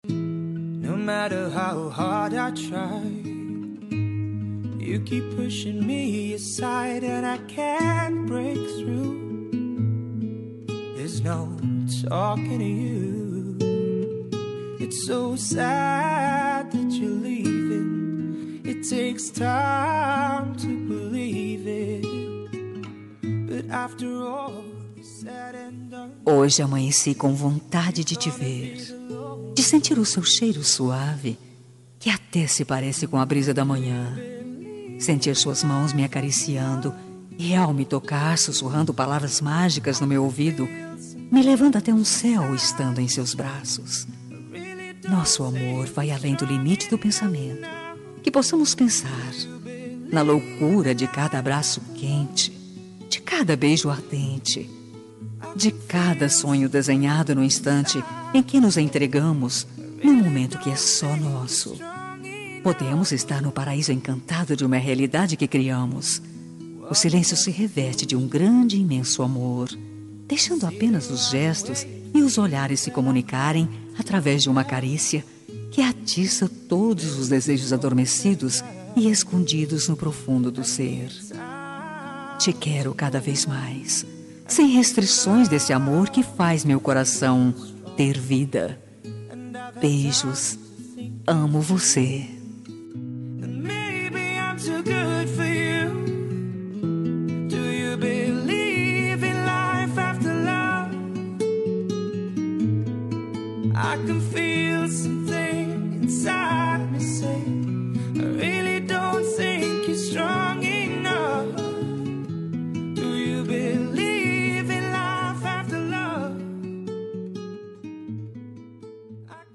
Telemensagem Romântica – Voz Feminina – Cód: 7859